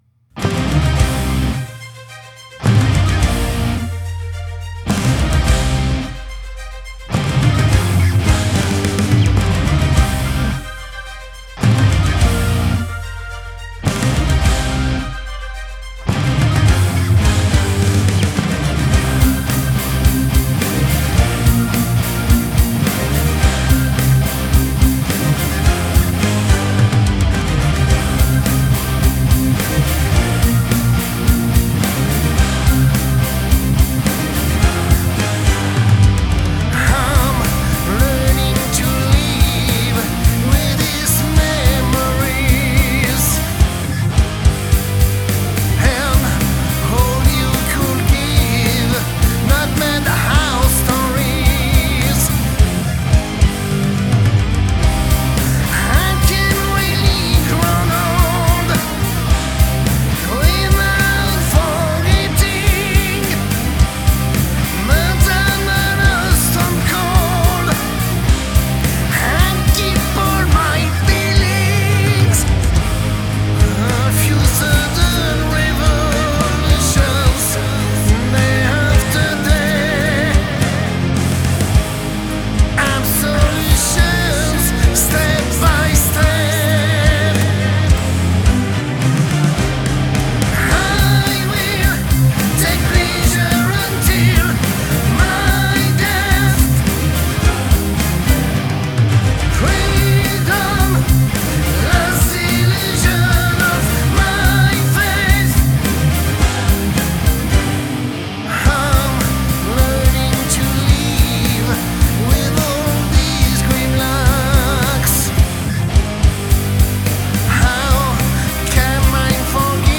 ♫ Melodic Power Metal ♫